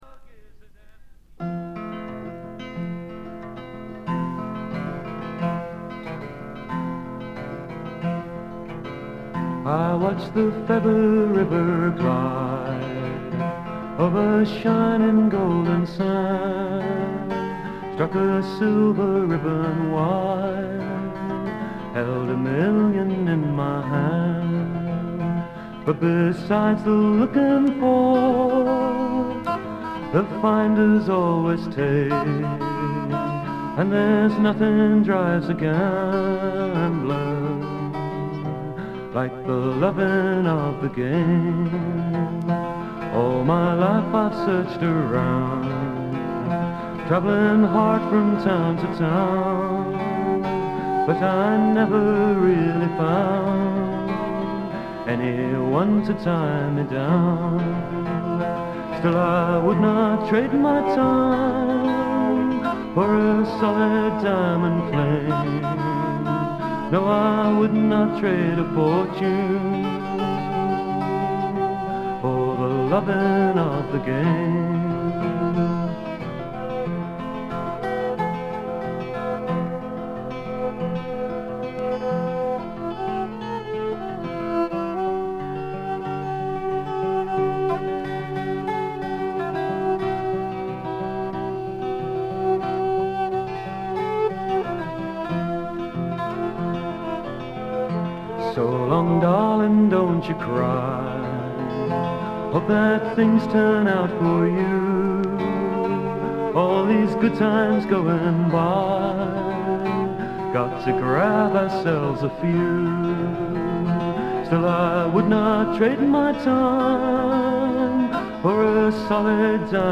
部分視聴ですが、ほとんどノイズ感無し。
全編を通じて飾り気のないシンプルな演奏で「木漏れ日フォーク」ならぬ「黄昏フォーク」といったおもむきですかね。
試聴曲は現品からの取り込み音源です。